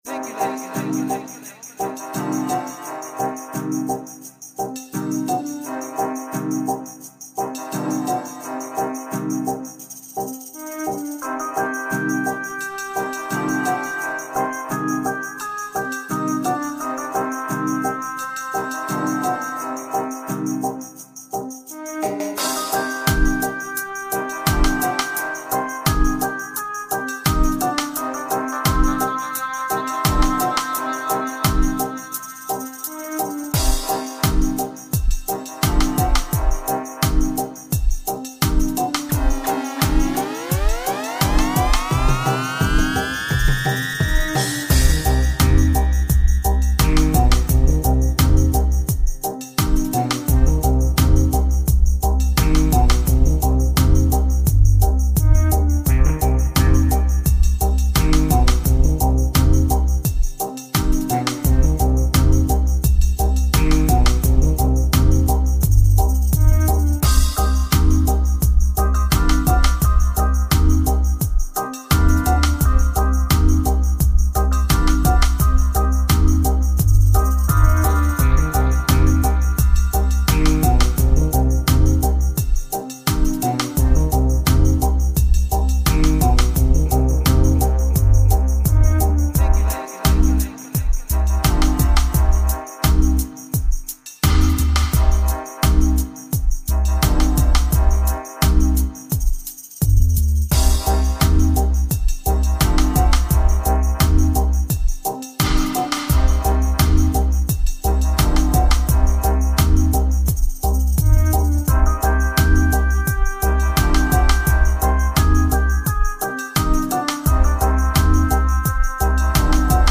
Dubplate